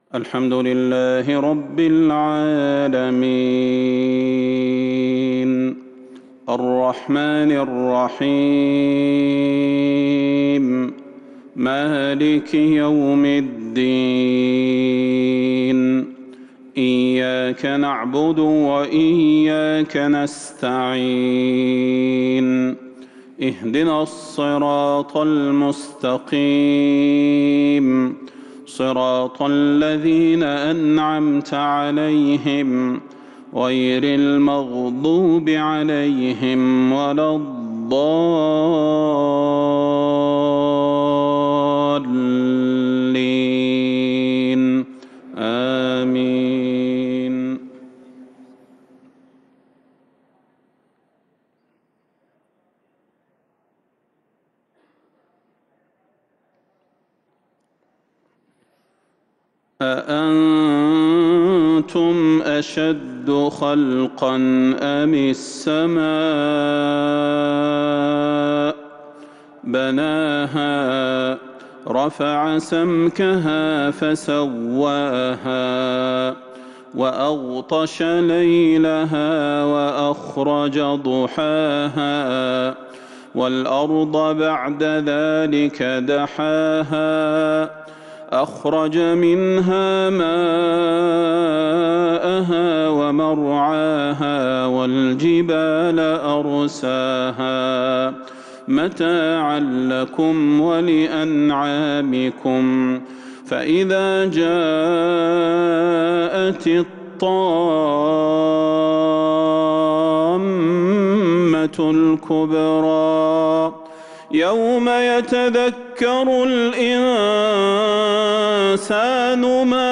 صلاة المغرب 1-2-1442 هـ من سورة النازعات | Maghrib prayer from Surah An-Naazi'aat 18/9/2020 > 1442 🕌 > الفروض - تلاوات الحرمين